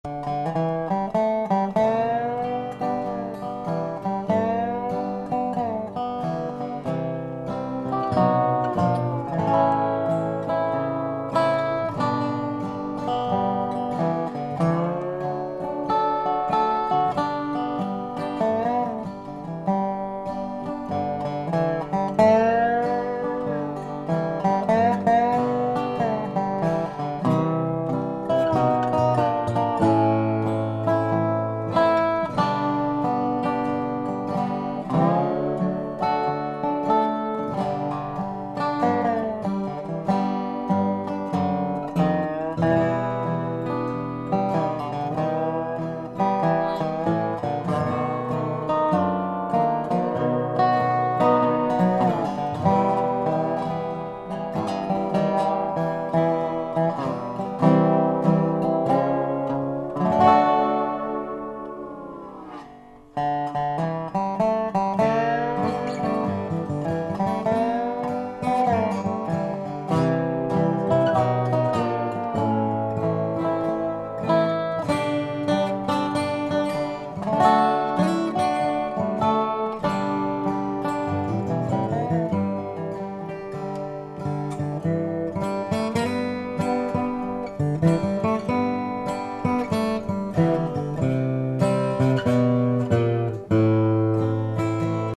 Vous avez accès à des petits exemples en MP3 en cliquant sur les dobros...